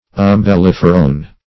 Umbelliferone \Um`bel*lif"er*one\, n. (Chem.)